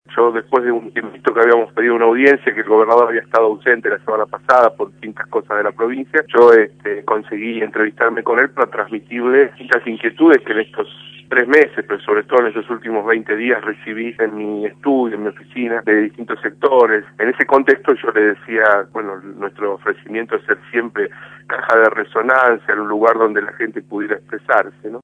Dio a conocer por Radio La Red que en el día de ayer logró entrevistarse con el Gobernador, ocasión en la que le transmitió una serie de reclamos que había recibido en su despacho en los últimos meses, “pero sobre todo en los últimos 20 días”.